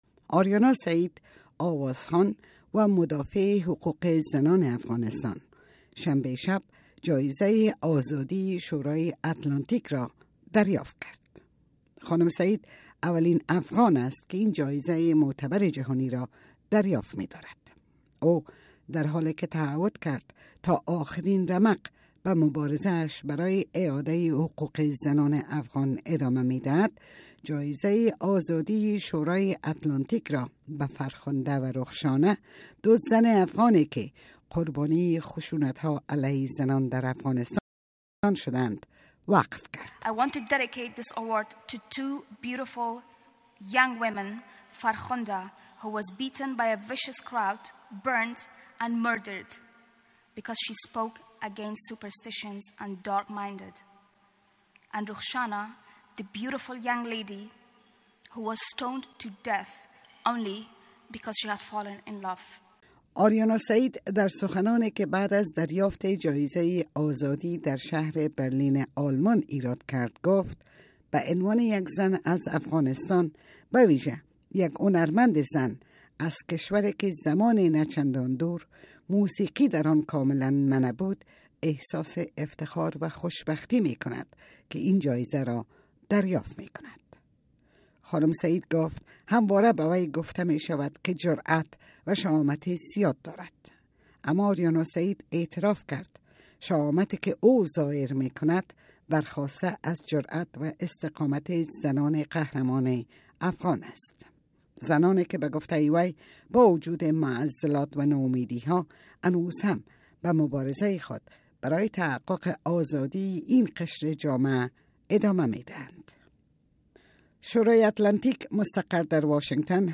گزارش رادیویی از مراسم اهدای جایزه آزادی شورای اتلانتیک برای آریانا سعید، هنرمند و مداقع حقوق زنان افغانستان